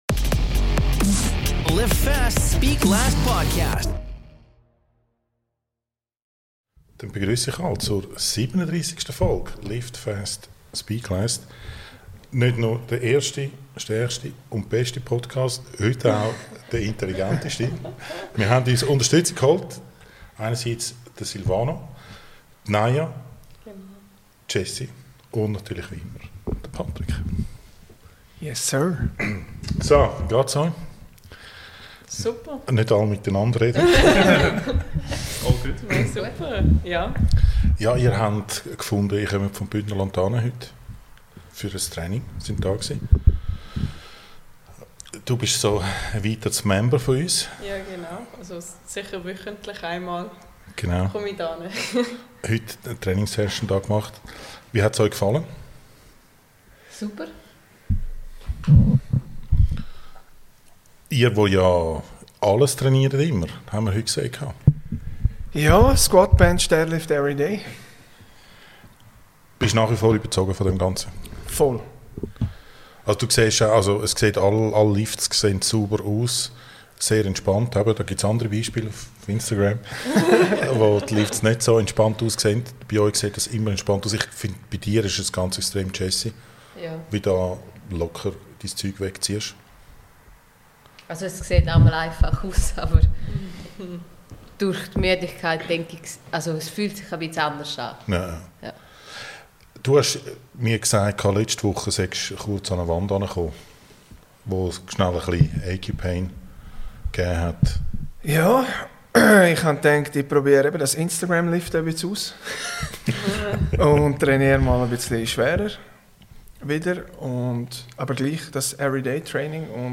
in einer spannenden Talkrunde, direkt aus dem One Rep Strength